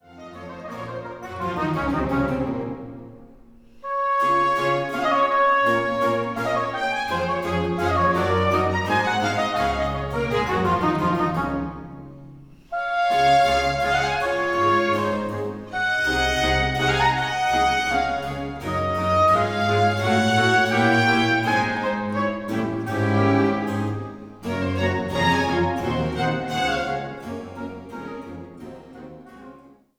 Orgel
Violine